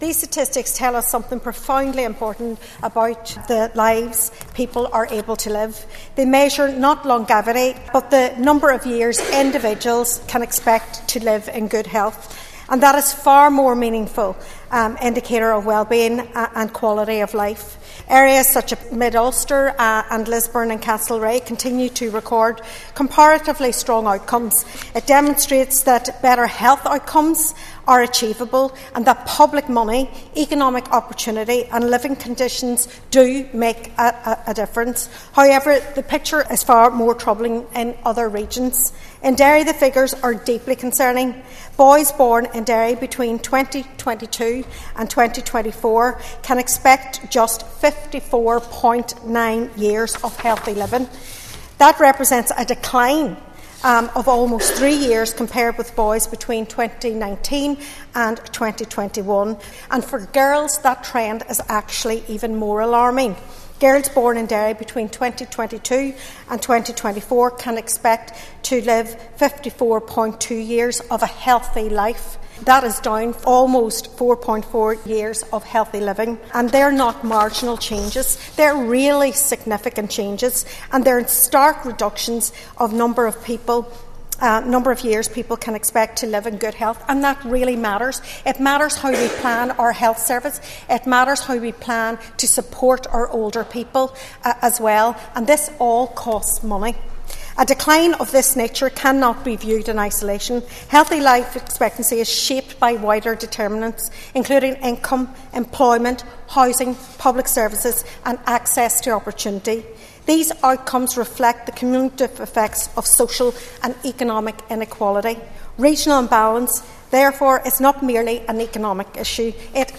The Assembly has been told that girls and boys born between 2022 and 2024 in the Derry City and Strabane Council area will have a lower healthy life expectancy than children born four years earlier.